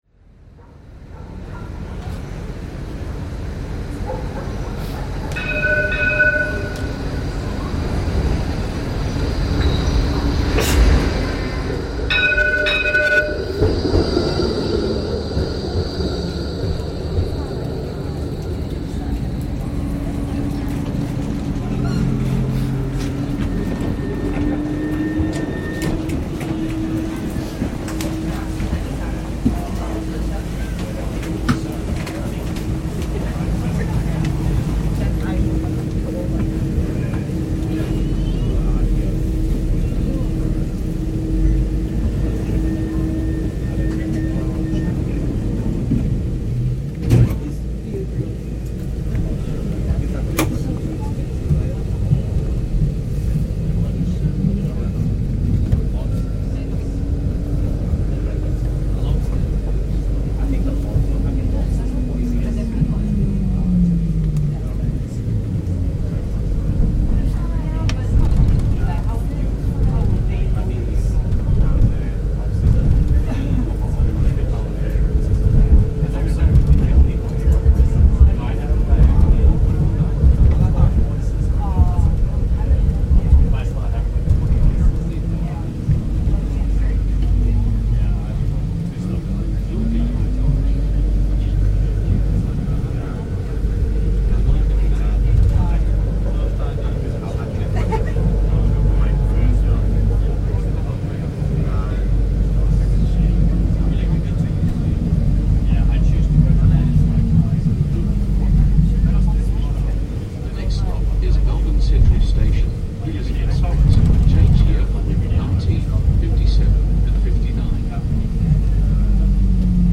This recording from 2025 captures a short commute using Melbourne's iconic trams in the city centre. It's not unusual for trams to be packed and this afternoon was no exception as many people were also heading to a football game. The clatter of the trams mingle with conversations and crosswalks, bringing the Melbourne CBD to life.